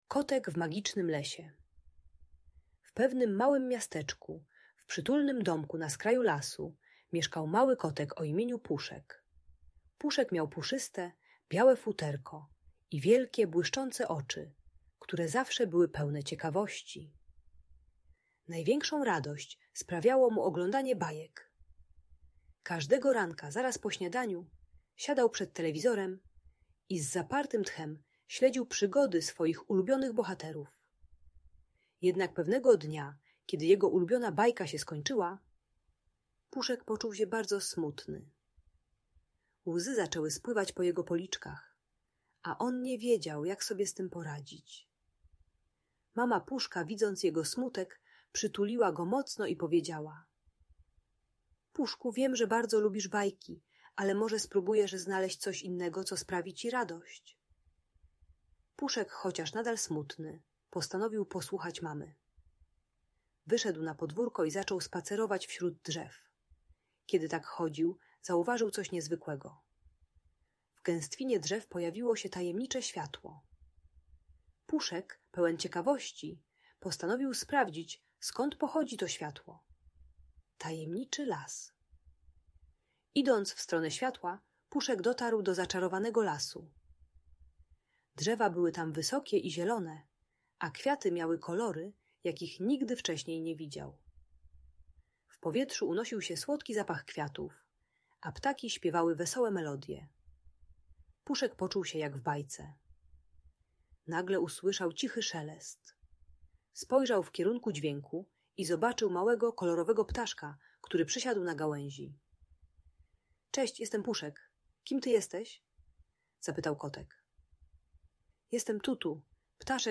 Historia Puszka w Magicznym Lesie - Audiobajka